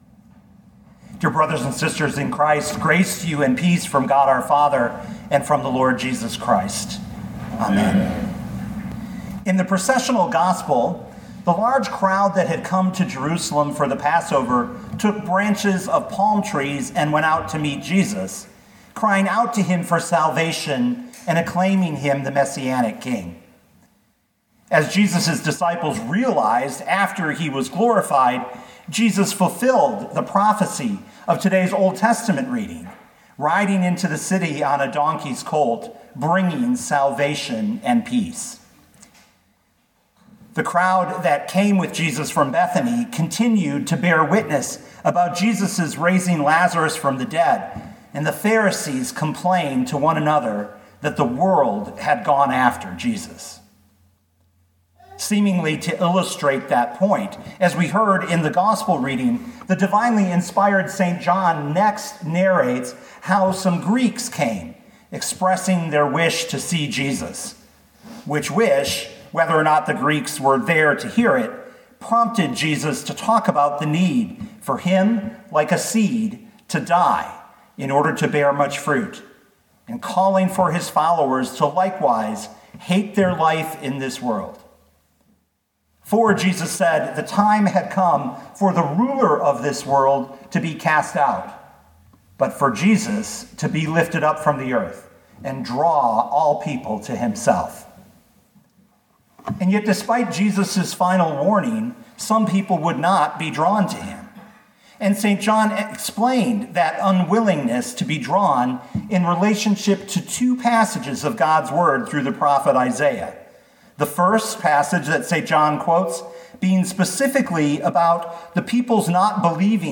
2021 John 12:20-43 Listen to the sermon with the player below, or, download the audio.